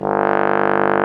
Index of /90_sSampleCDs/Roland L-CDX-03 Disk 2/BRS_Trombone/BRS_Tenor Bone 4